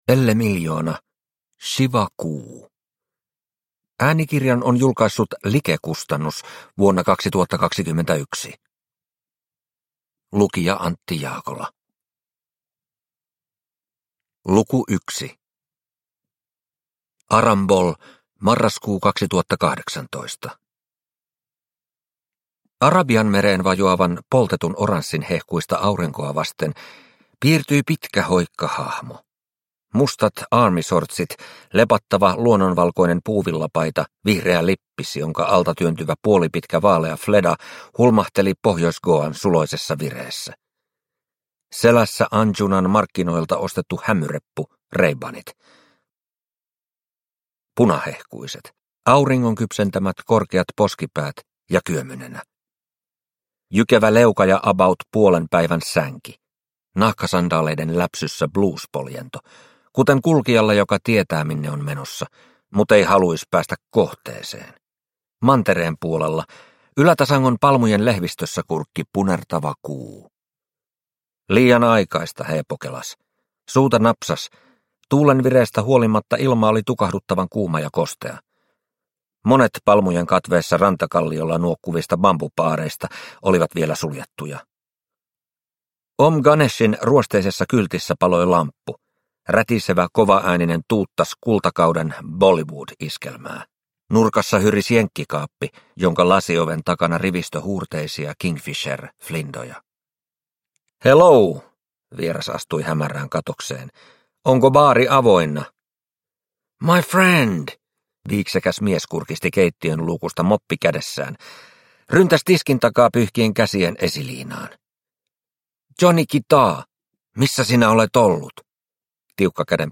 Shiva-kuu – Ljudbok – Laddas ner